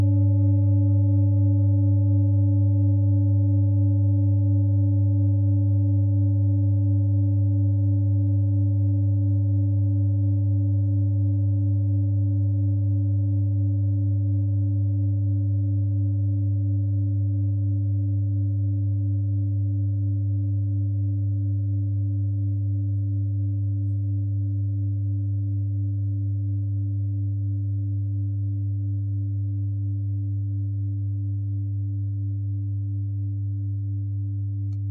Klangschale Bengalen Nr.22
Klangschale-Gewicht: 1780g
Klangschale-Durchmesser: 25,7cm
Die Klangschale kommt aus einer Schmiede in Bengalen (Ostindien). Sie ist neu und wurde gezielt nach altem 7-Metalle-Rezept in Handarbeit gezogen und gehämmert.
(Ermittelt mit dem Filzklöppel oder Gummikernschlegel)
klangschale-ladakh-22.wav